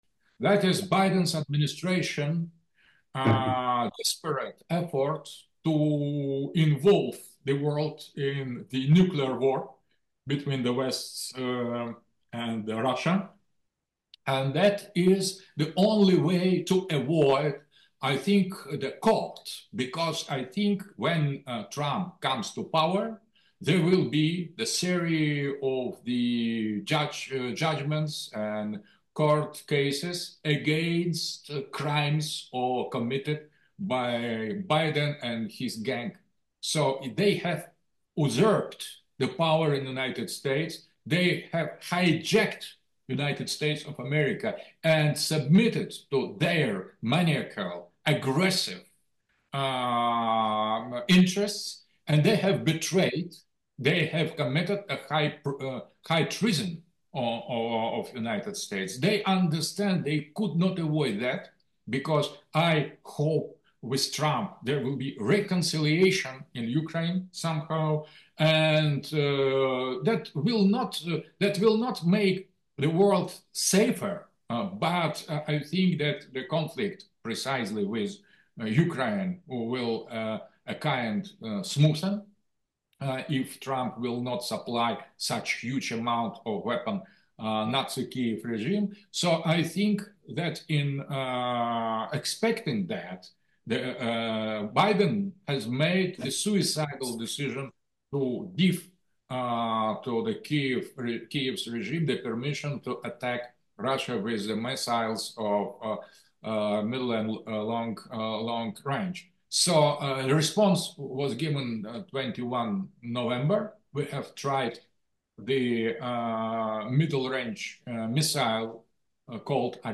Alexander Dugin's Kathmandu Conference speech 3
speech 3